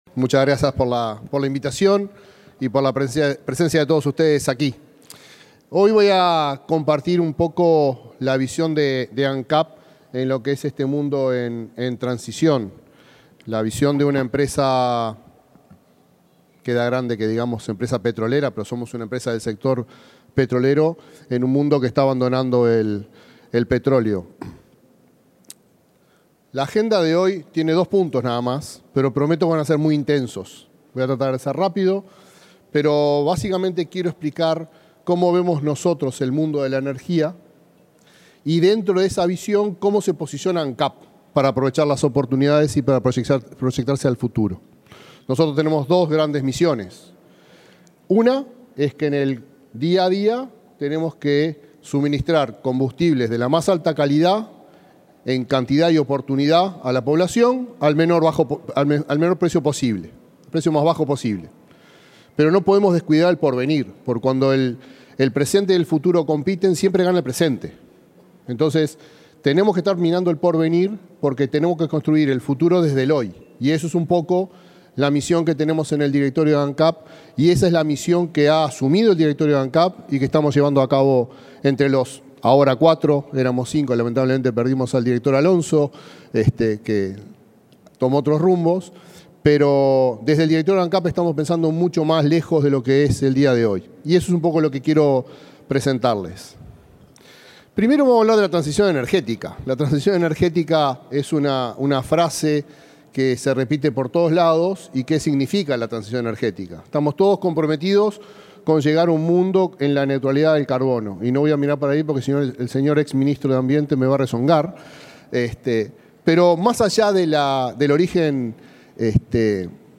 Disertación del presidente de Ancap, Alejandro Stipanicic
Disertación del presidente de Ancap, Alejandro Stipanicic 23/08/2023 Compartir Facebook X Copiar enlace WhatsApp LinkedIn El presidente de Ancap, Alejandro Stipanicic, disertó, este miércoles 23, en un desayuno de trabajo de Somos Uruguay, realizado en el aeropuerto de Carrasco.